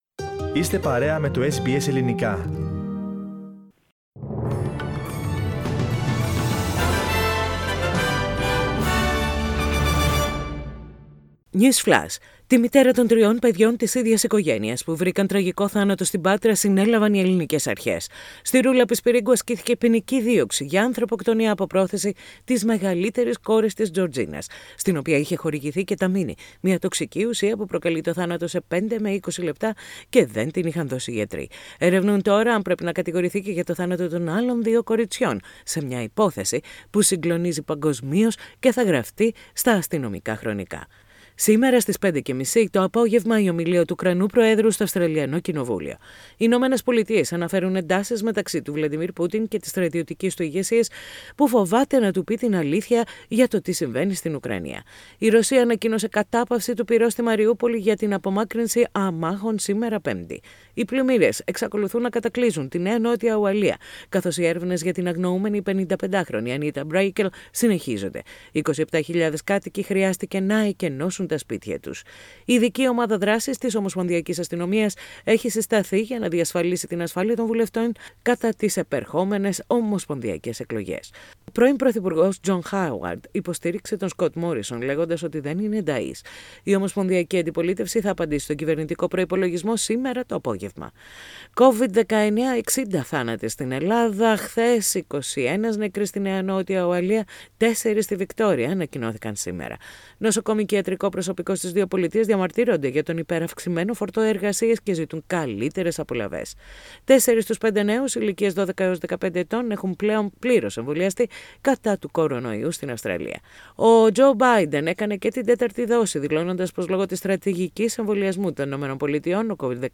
News Flash - Σύντομο Δελτίο Ειδήσεων - Πέμπτη 31.3.22